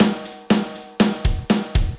Finally I wanted to bring a bit of coherence to the kit so I set up a plate style reverb with about 1s decay, no pre-delay and with the low end rolled off in the EQ section of the return channel to prevent the kick drum 'muddying' the reverb return.
As you can hear the reverb helps to bring the kit together into a defined 'space':